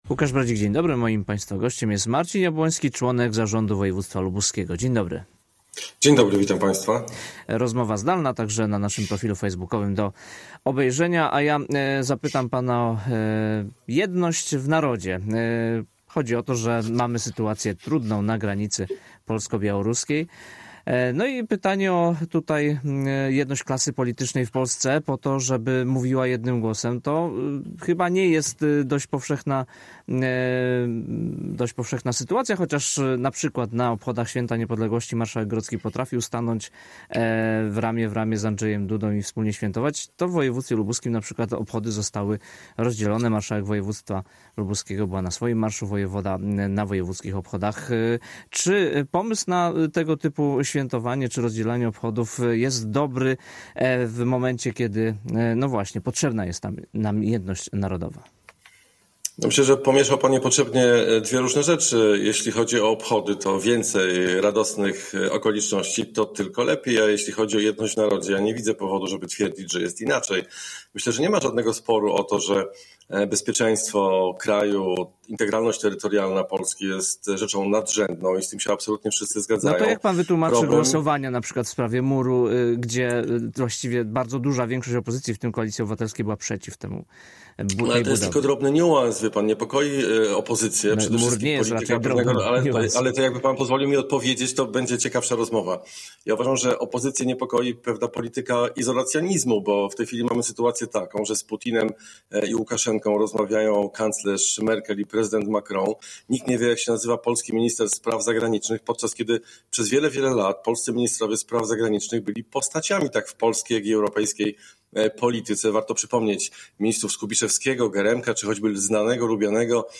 Z członkiem zarządu woj. lubuskiego i działaczem PO rozmawia